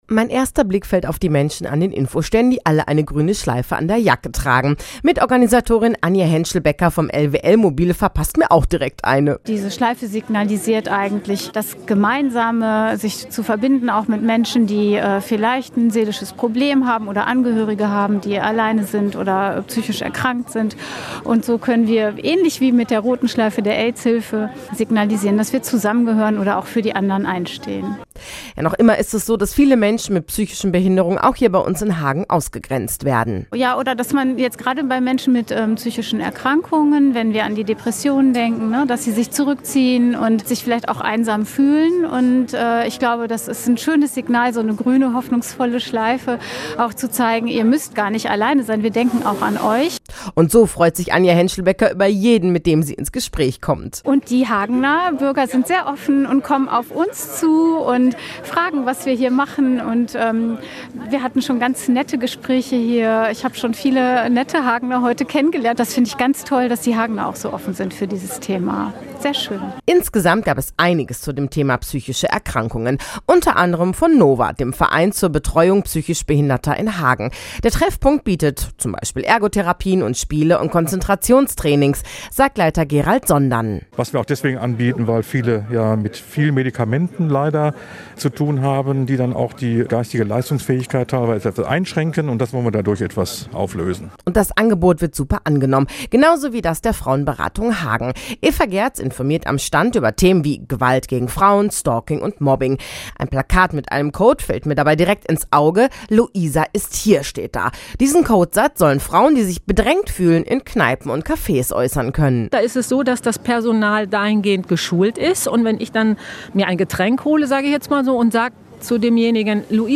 war am Nachmittag auf dem Friedrich-Ebert-Platz und hat sich dort mal umgesehen.